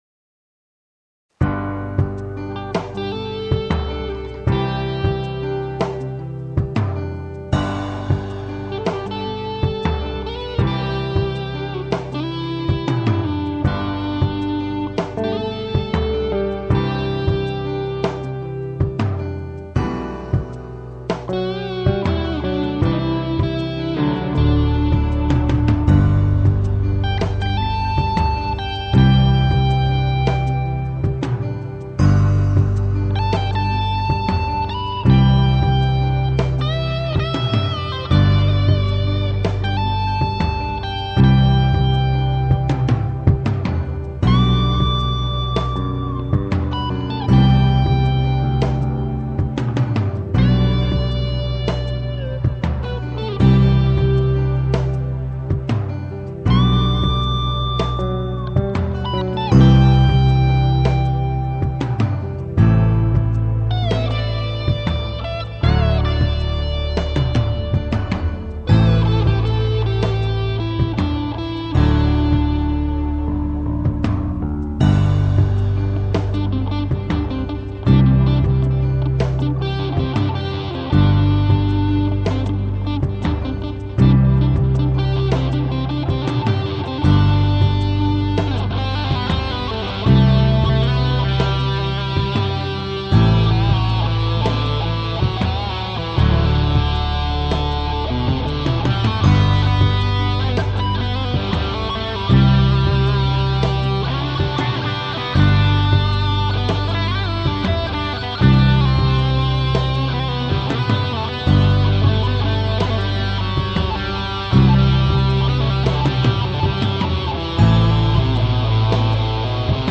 Darum benutze ich Hintergrundmusik, wenn ich spiele.